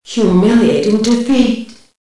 Index of /cstrike/sound/female